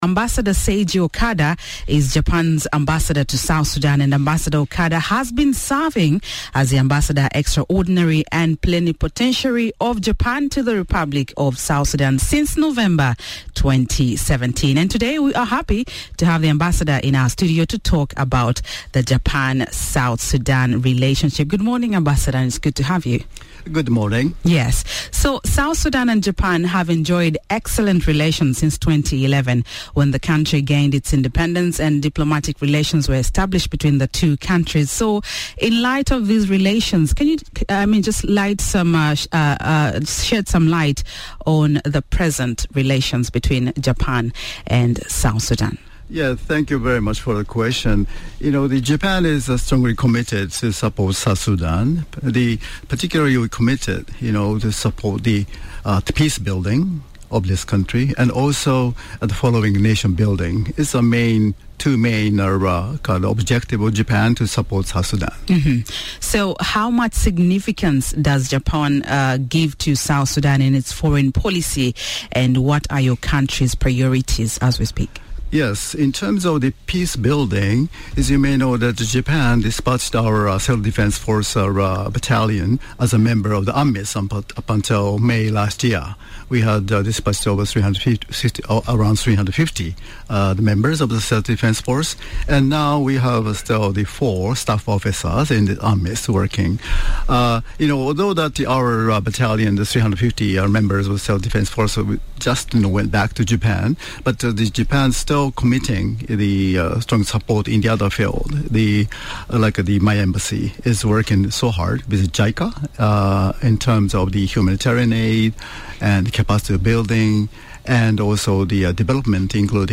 Ambassador Seiji Okada discusses Japan - South Sudan Relations